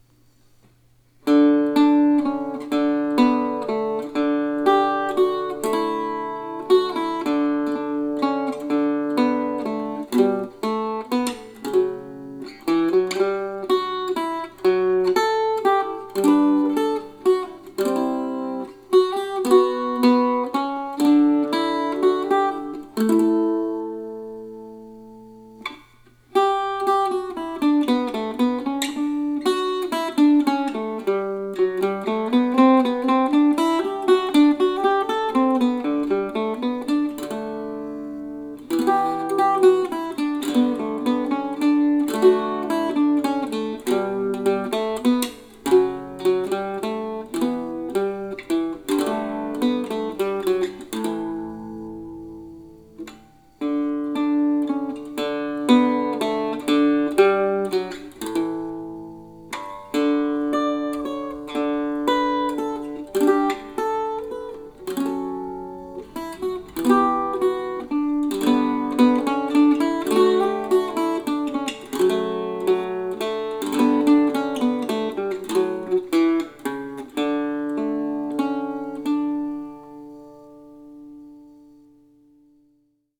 for mandocello or octave mandolin.